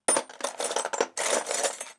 SFX_Metal Sounds_03.wav